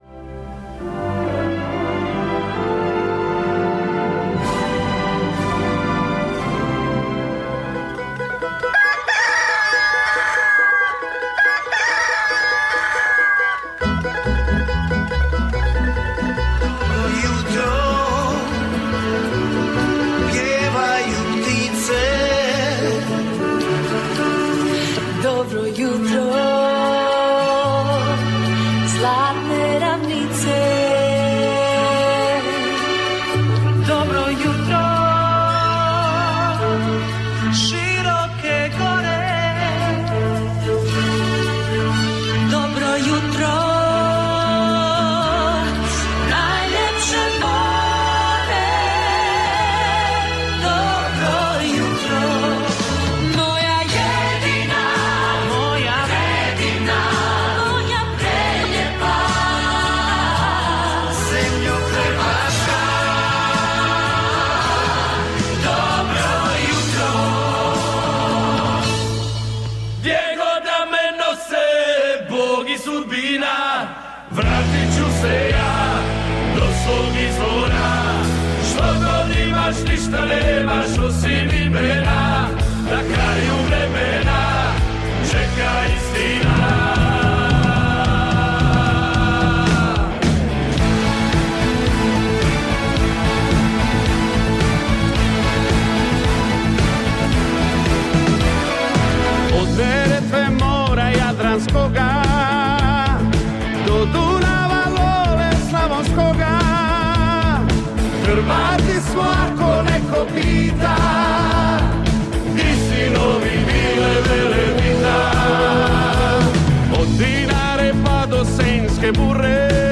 Croatian radio, music and news in Vancouver, RadioVRH 1470 AM is proud to present croatian music and news on the air waves.